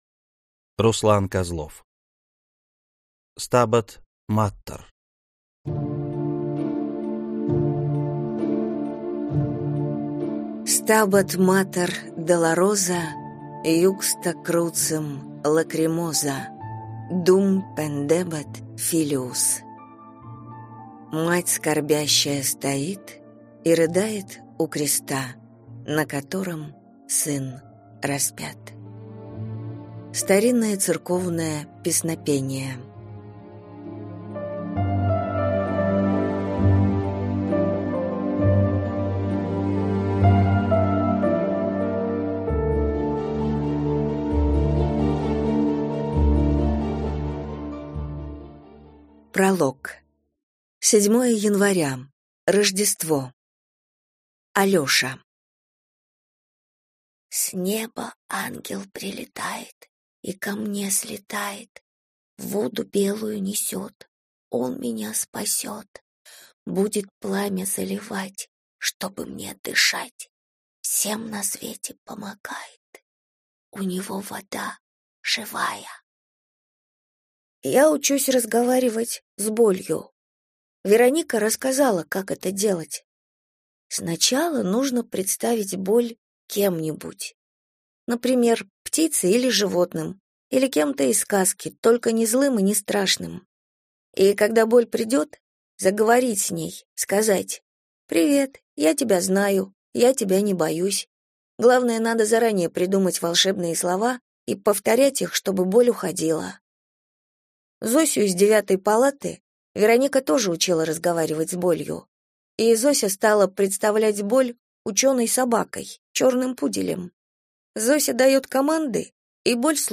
Аудиокнига Stabat Mater | Библиотека аудиокниг
Прослушать и бесплатно скачать фрагмент аудиокниги